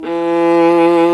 Index of /90_sSampleCDs/Roland - String Master Series/STR_Viola Solo/STR_Vla2 % marc